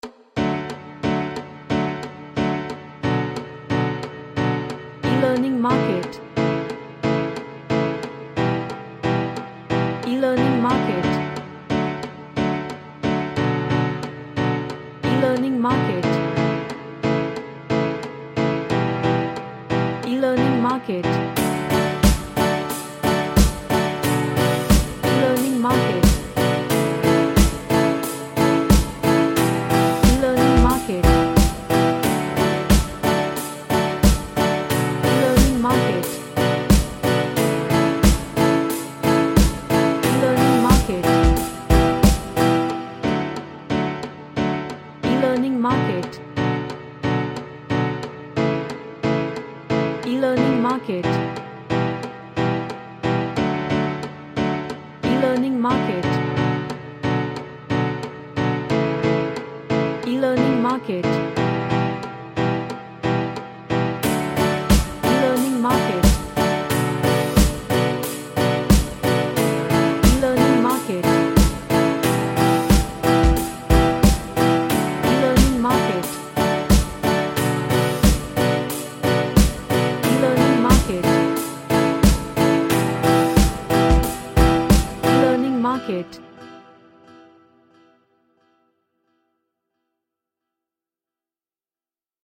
A reggae genre track with great groove.
Happy